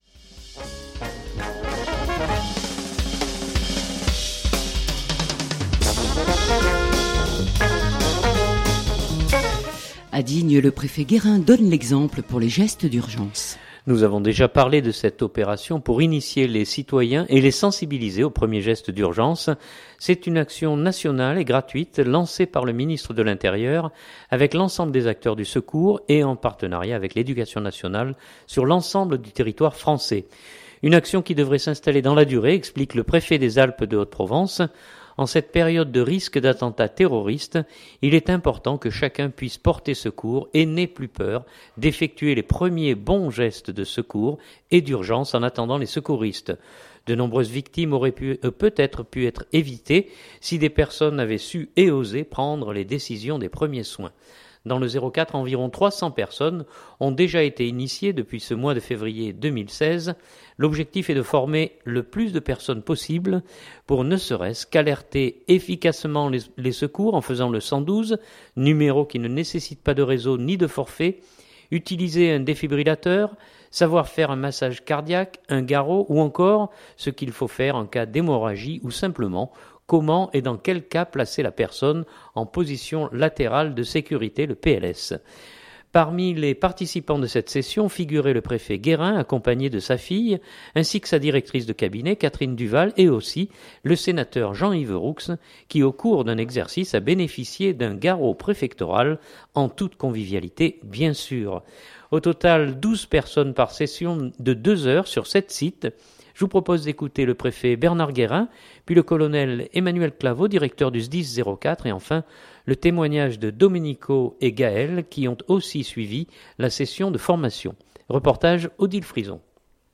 Nous entendrons également le témoignage de 2 sapeurs pompiers en charge de la formation de cette matinée.